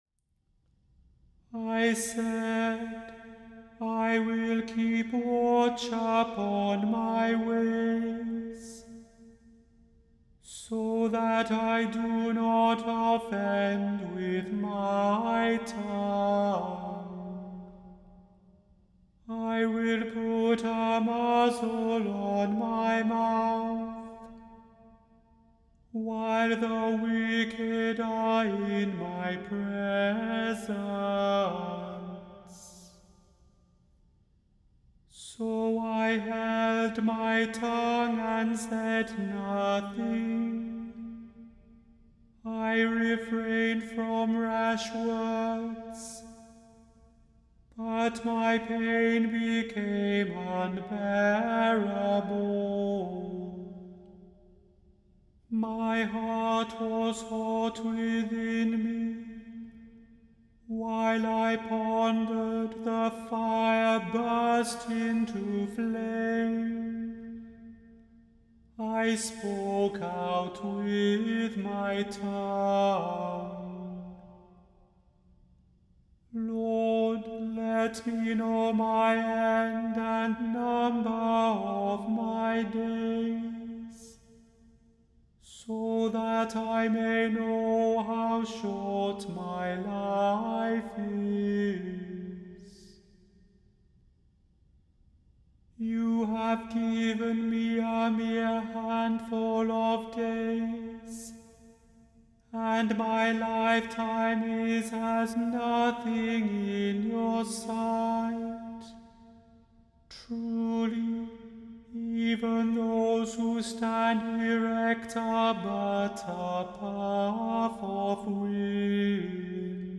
The Chant Project – Psalm 39 vs 1-8